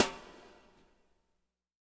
BW BRUSH04-R.wav